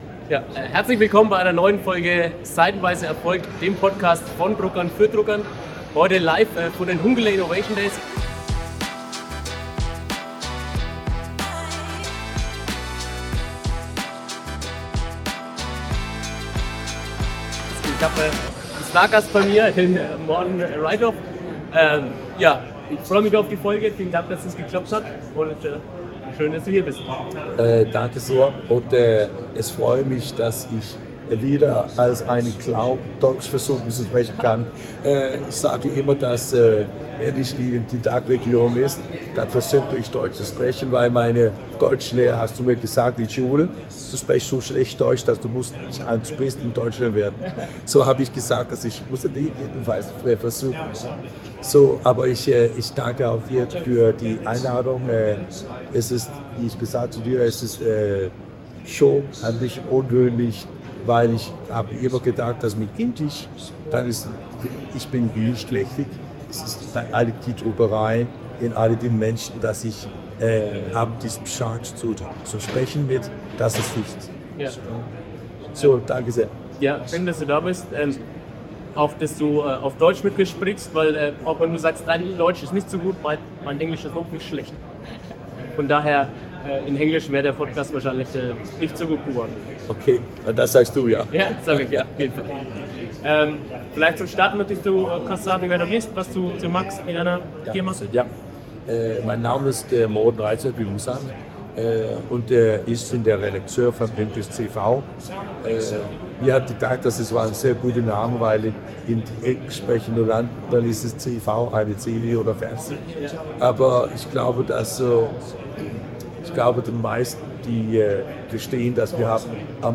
Dieses Mal gibt es wieder mal einen Podcast direkt von der Messe aufs Ohr. Zurzeit finden in Luzern die Hunkeler Innovationdays statt.
PS: Bitte entschuldigt die Tonqualität. Allerdings entschädigt der Inhalt für die etwas schlechtere Qualität.